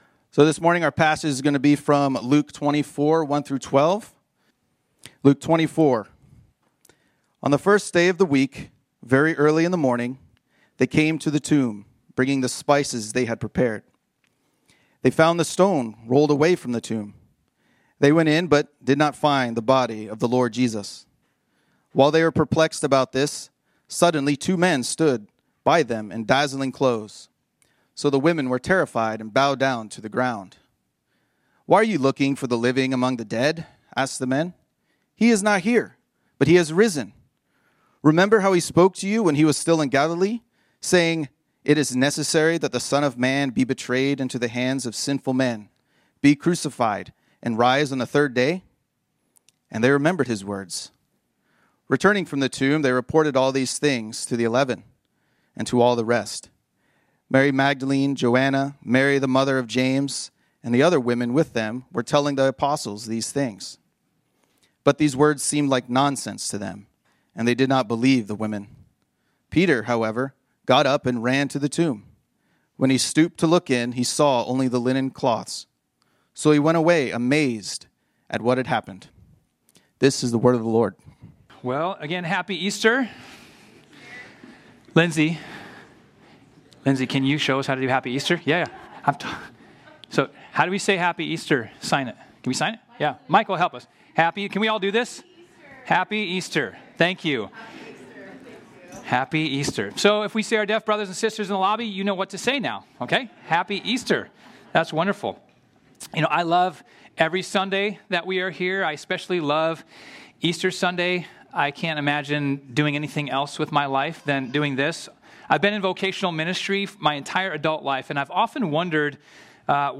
This sermon was originally preached on Sunday, April 20, 2025.